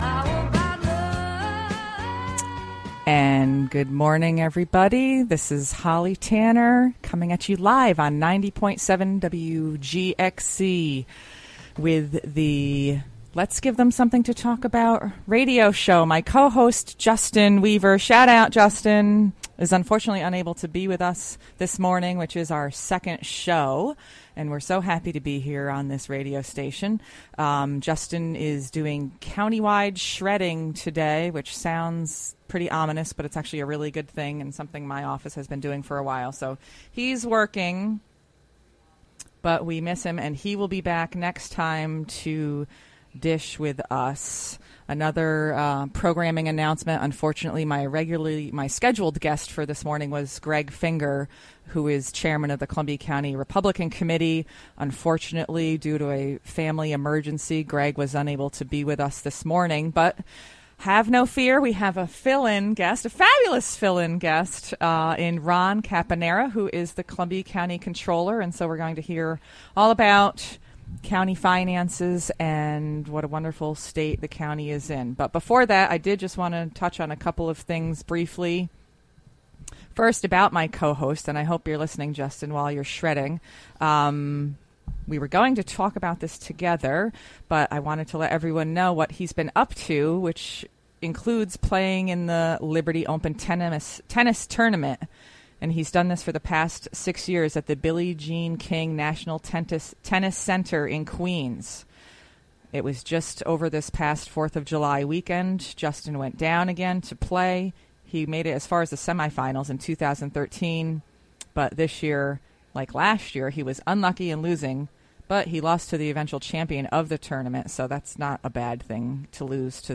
11am Live interview with Columbia County Controller Ron...